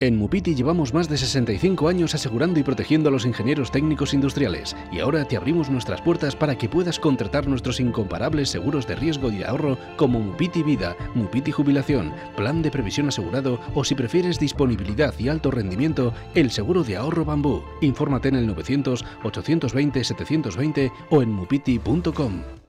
cuna radio.mp3